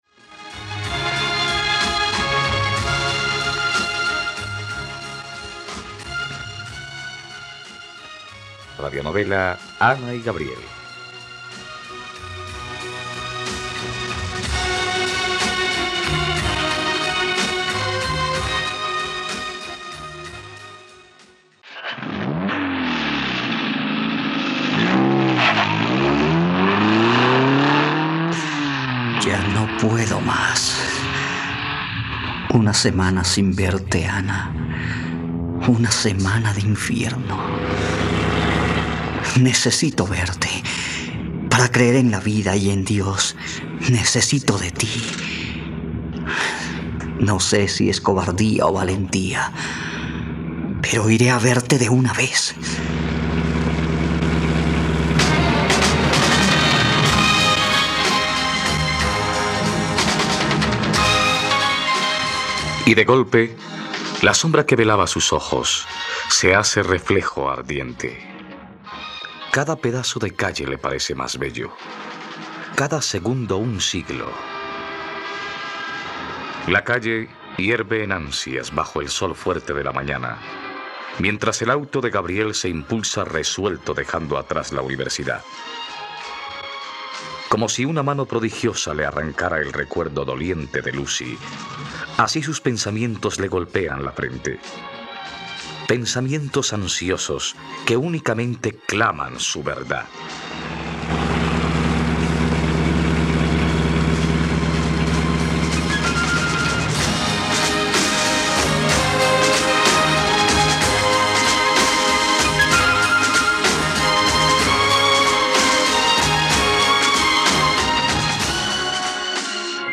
..Radionovela. Escucha ahora el capítulo 55 de la historia de amor de Ana y Gabriel en la plataforma de streaming de los colombianos: RTVCPlay.